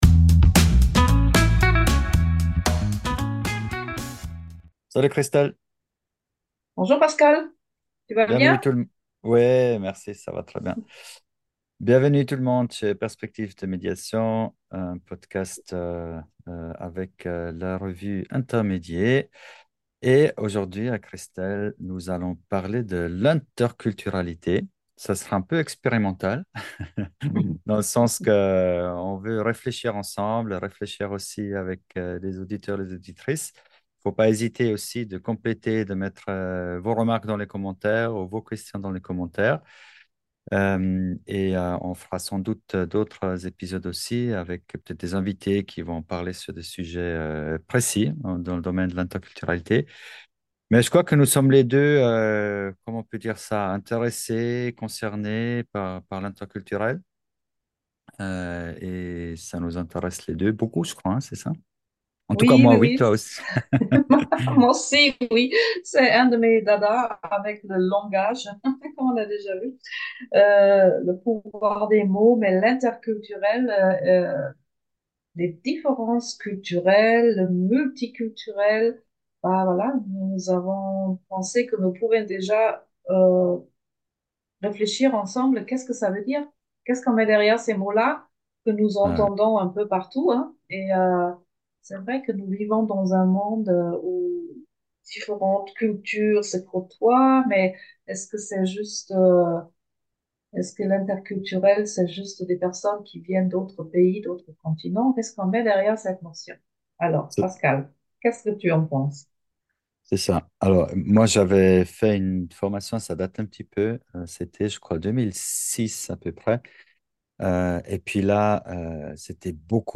Plongez dans un dialogue captivant entre deux experts en médiation